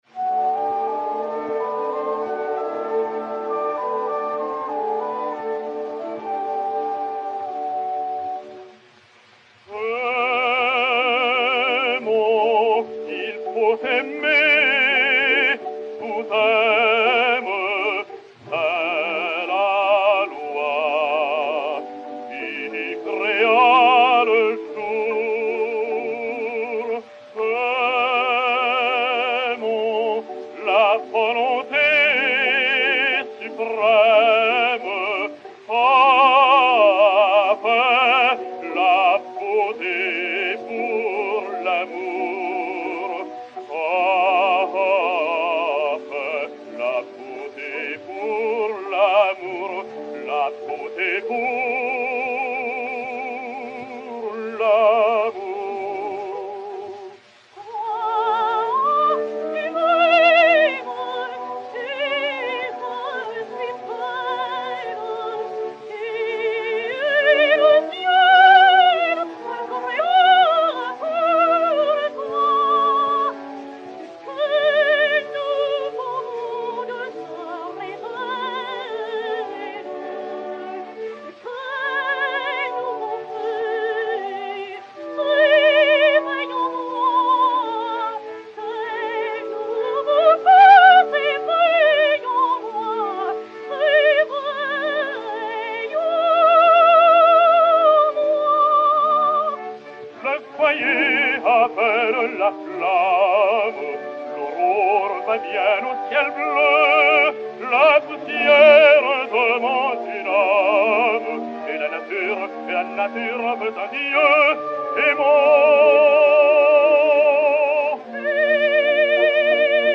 Duo "Aimons, il faut aimer"
Rose Heilbronner (Galathée), Joachim Cerdan (Pygmalion) et Orchestre
enr. à Paris le 17 décembre 1912